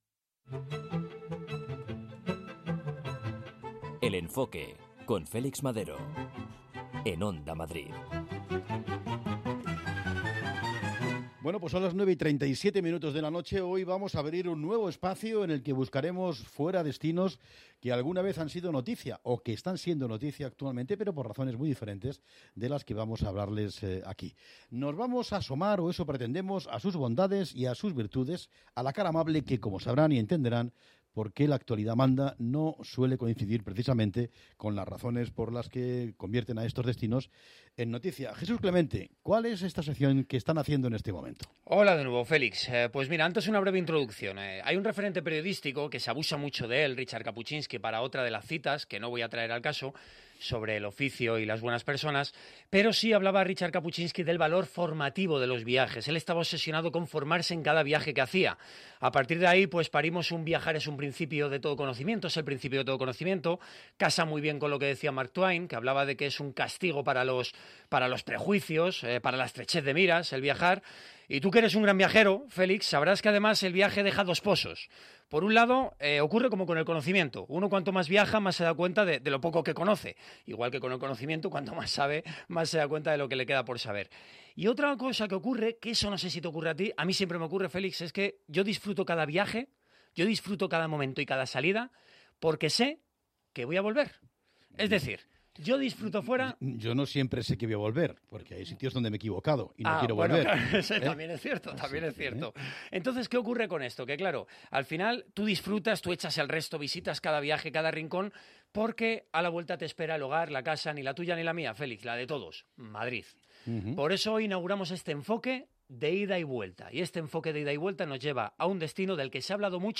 Al ritmo de la música local, reponiendo energías en los lugares más recomendables para comer y dormir.